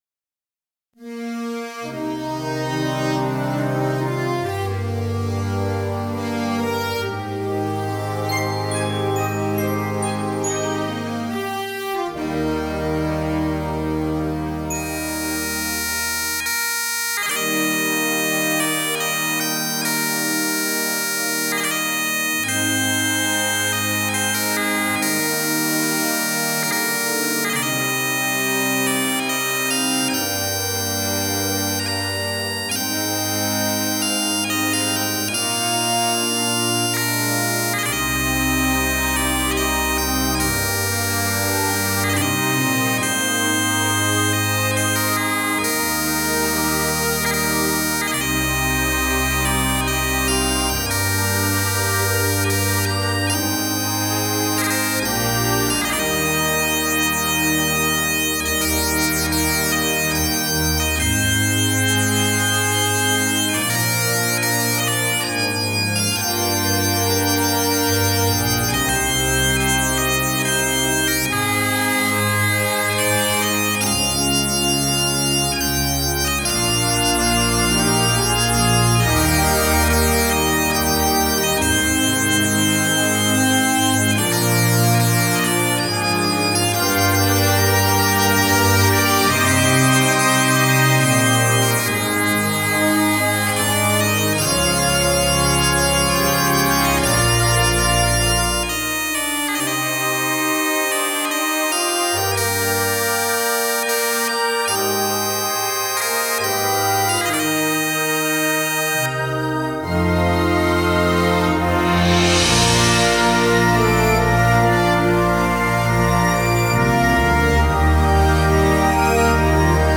Timpani
Glockenspiel